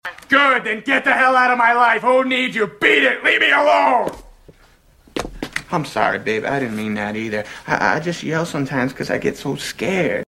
Mazda Rx7 FD Life For Sound Effects Free Download